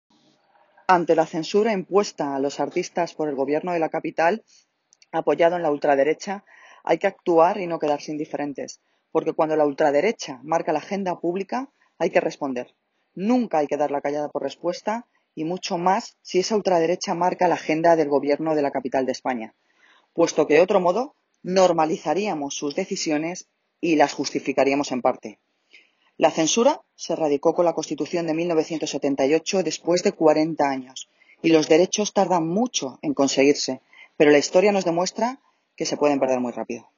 Audio - Noelia Posse (Alcaldesa de Móstoles) sobre actuacion Luis Pastor Móstoles